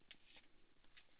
osa 0918 (Monaural AU Sound Data)